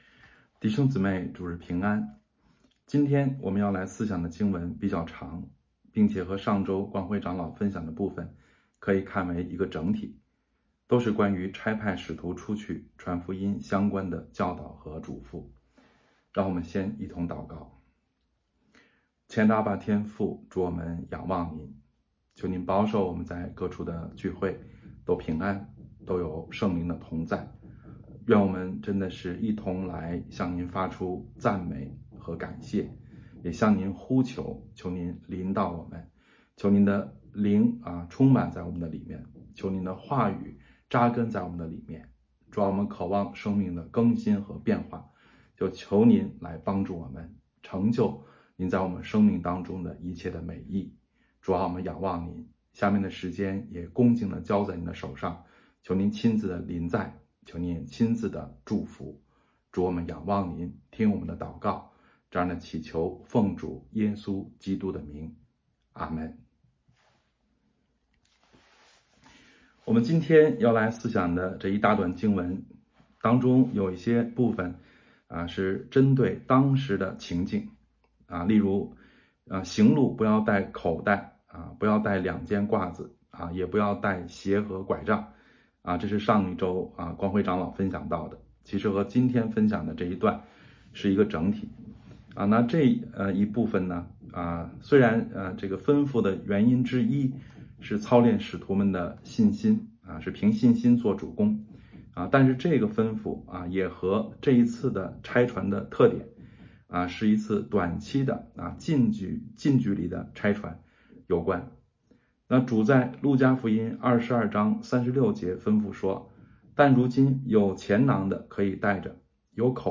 北京守望教会2025年5月4日主日敬拜程序